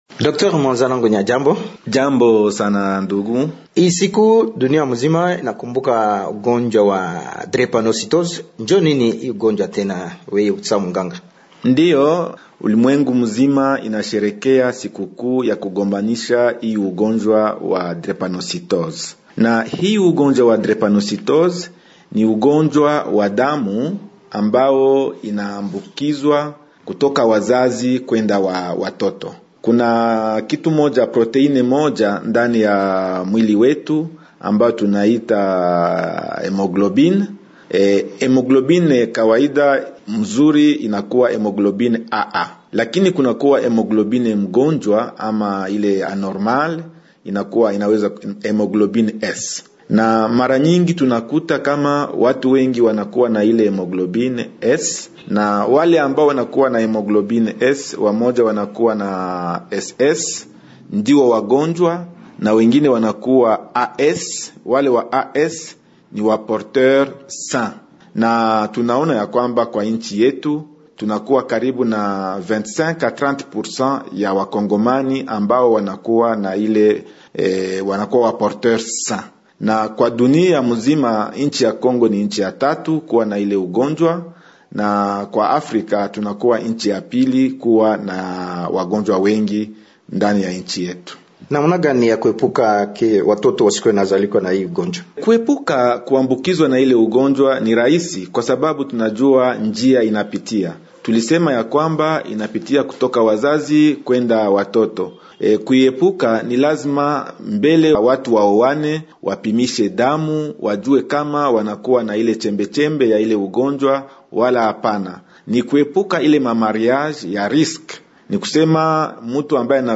invite_du_jeudi_web.mp3